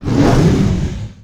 wav / general / combat / creatures / dragon / he / hurt1.wav
hurt1.wav